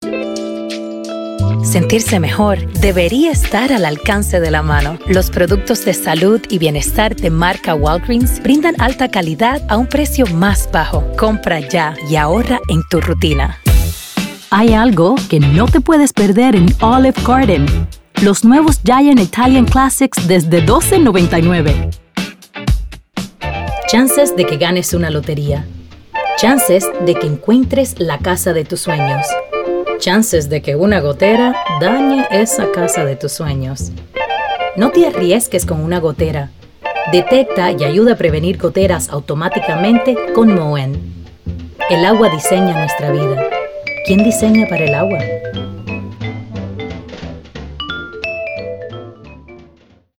Spanish Commercial